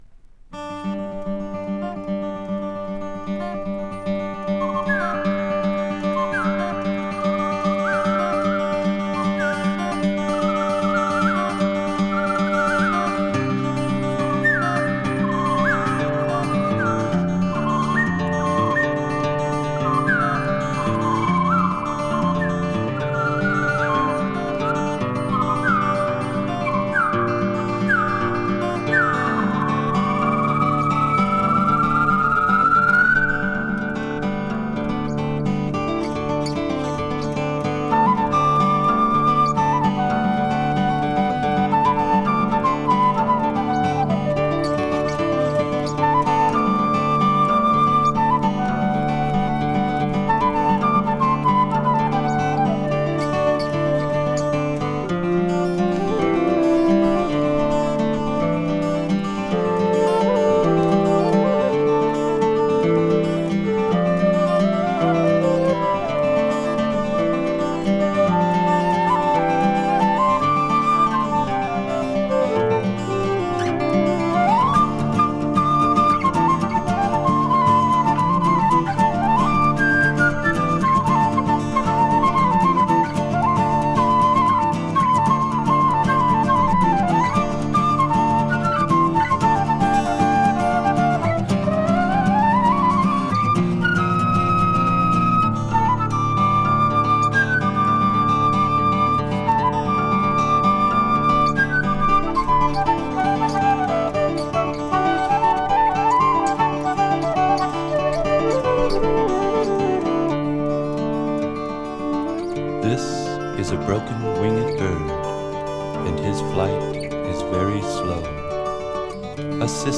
【JAZZ FUNK】 【ETHNIC】 cat number
World music ensemble from US!
multi-instrumentalists